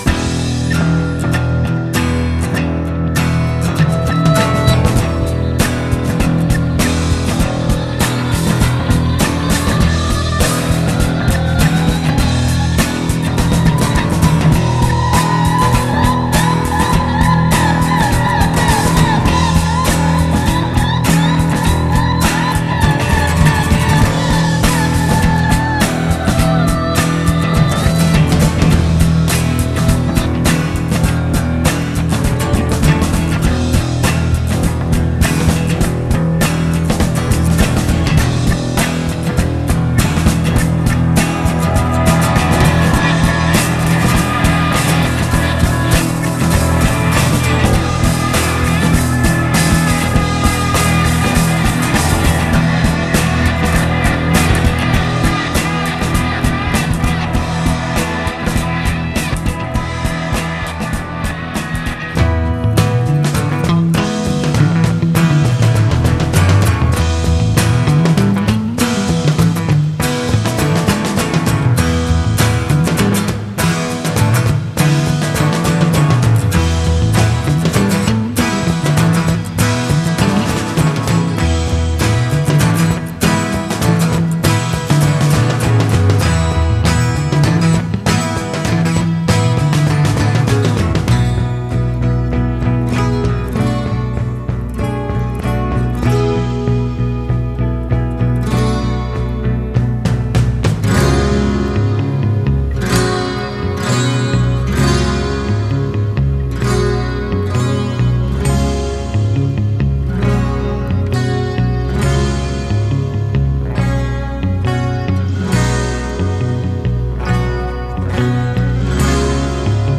guitar and vocals
bass and French horn
drums
Recorded at I.B.C. Studios, London
C 1:31 bridge1 guitar chords (suspensions)
E 2:47 bridge2 enter French horn
E 3:05 bridge2 diminish intensity